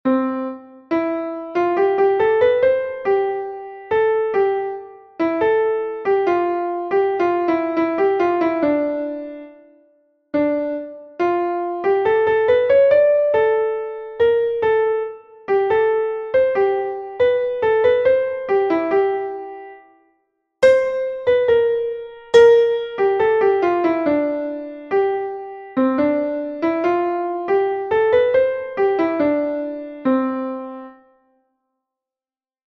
Rhythmic reading 1
Exercise 1:  semiquaver and dotted quaver   dotted quaver and semiquaver
Key of C major, with no accidentals in the key signature.